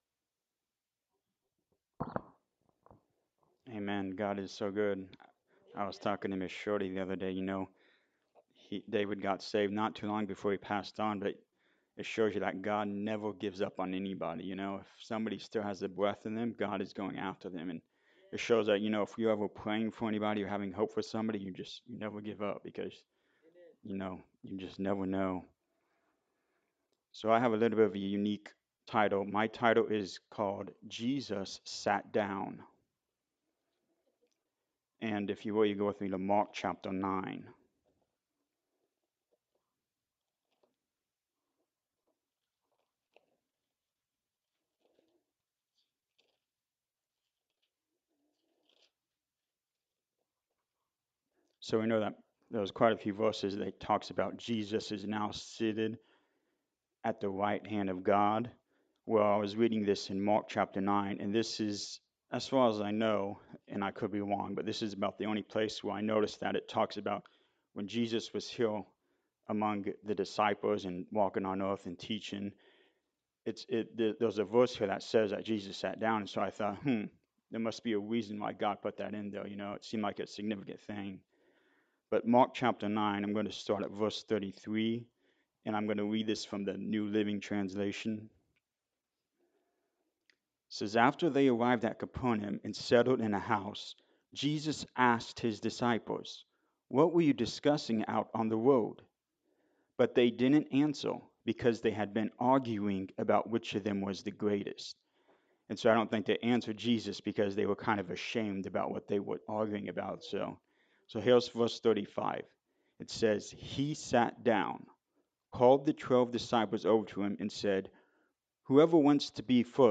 Service Type: Sunday Morning Service
Sunday-Sermon-for-February-9-2025.mp3